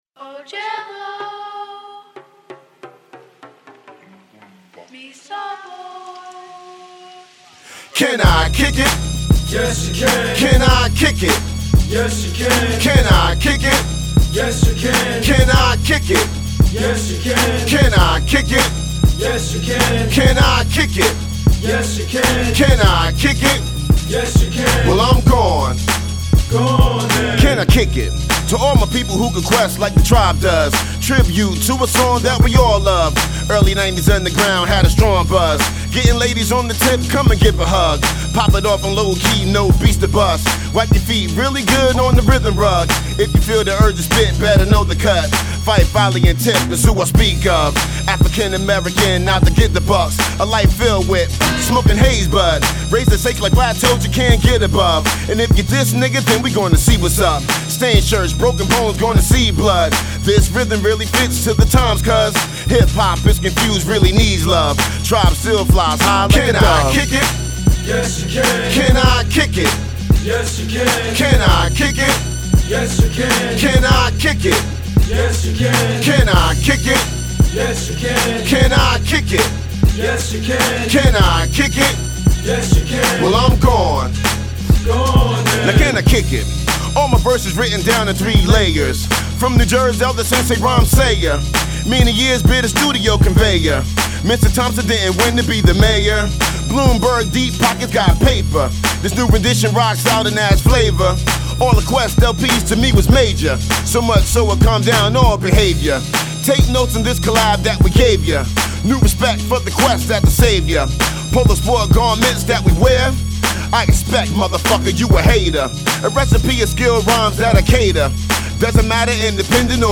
classics remixed